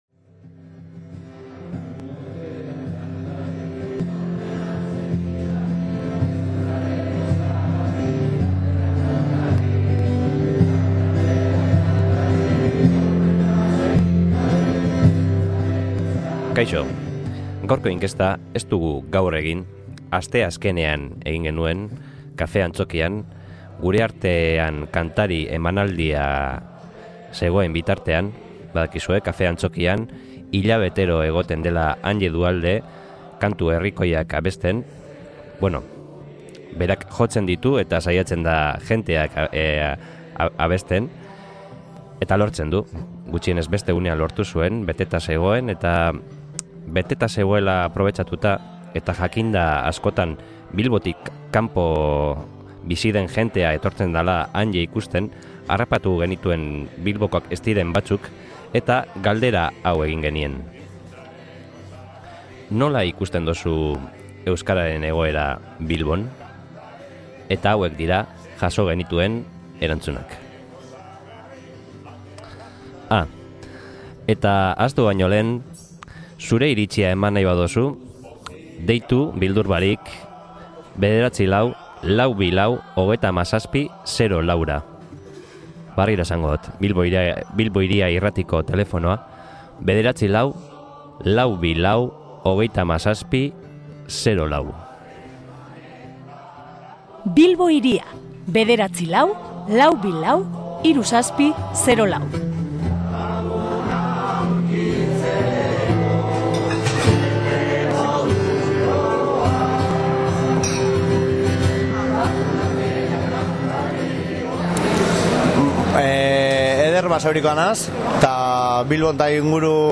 INKESTA
SOLASALDIA